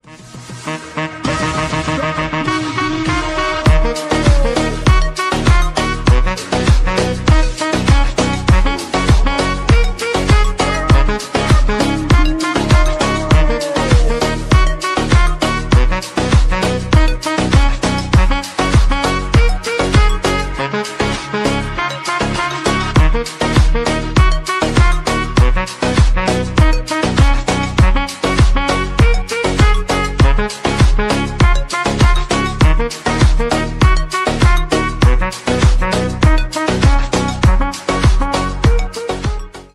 Ремикс
клубные # без слов